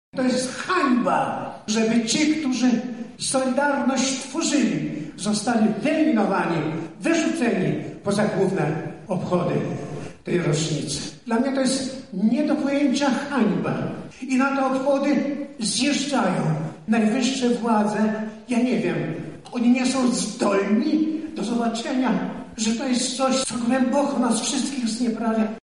Korzystając z okazji uroczystości Ojciec Ludwik Wiśniewski skomentował ostatnie obchody sierpniowe które odbyły się w Gdańsku: